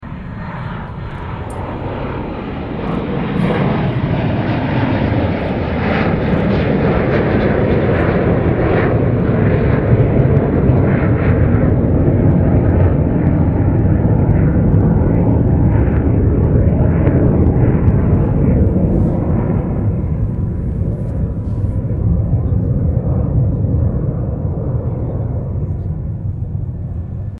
新田原基地航空祭 帰投篇
音を開くと、大きな音がします。ご注意ください！
左：RF-4E　百里基地